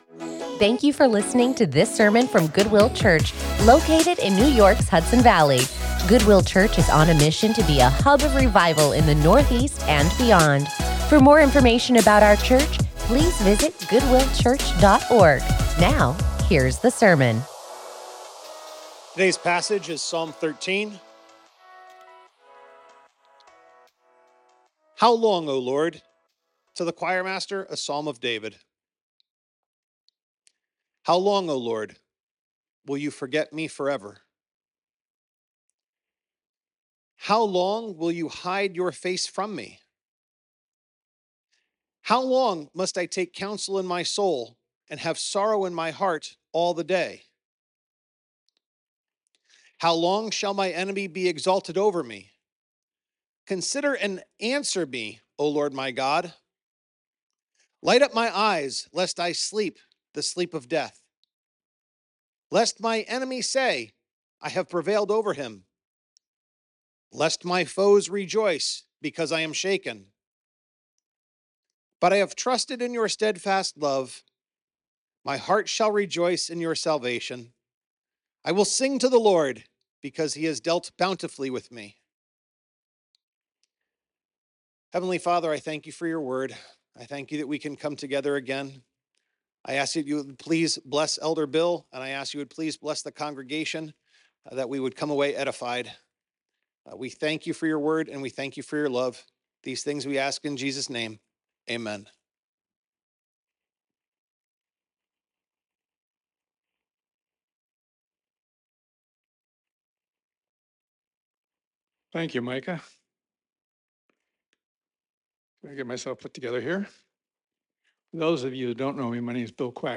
Join us in the study of God's Word as we take a break from our sermon series with this sermon